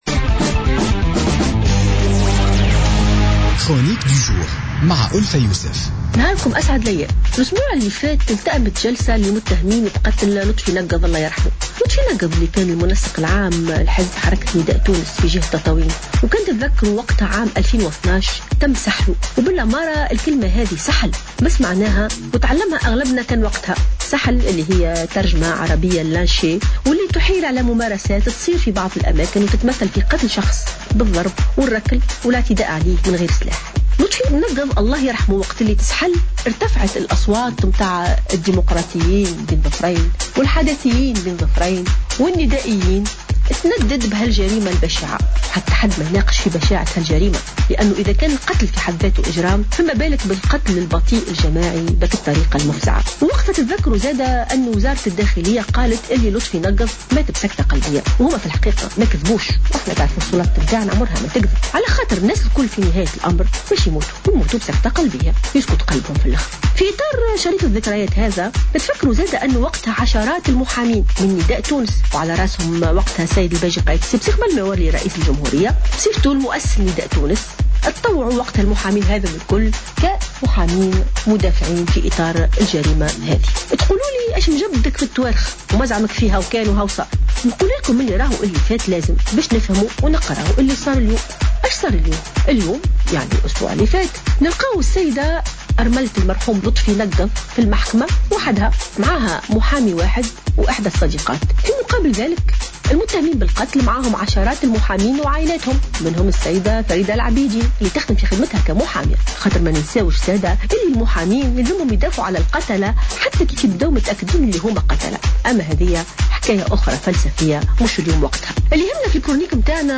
استنكرت المفكرة والجامعية ألفة يوسف، في افتتاحية اليوم الأربعاء تنكّر بعض السياسيين لدماء الشهداء و تعمّدهم طي ملفات كانت في الأمس القريب الورقة الرابحة في الحملات الانتخابية لكسب الأصوات.